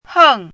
chinese-voice - 汉字语音库
heng4.mp3